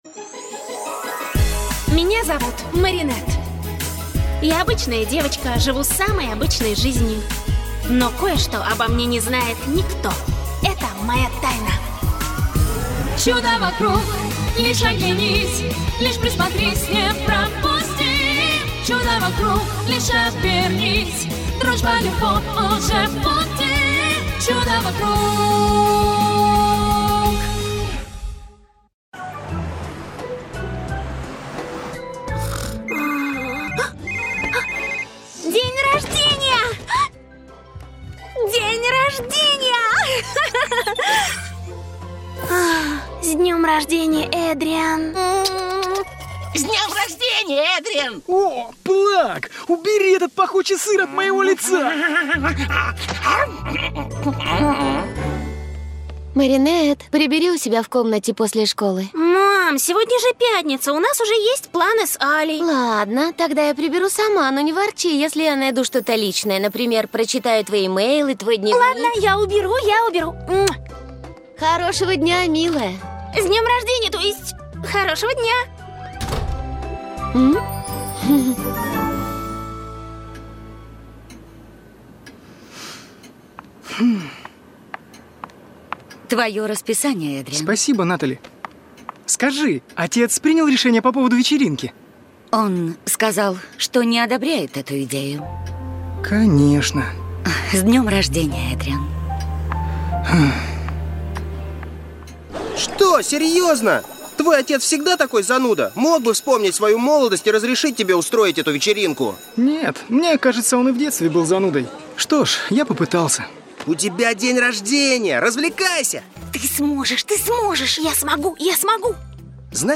Леди Баг и Супер-Кот - аудиосказка по мультфильму - слушать онлайн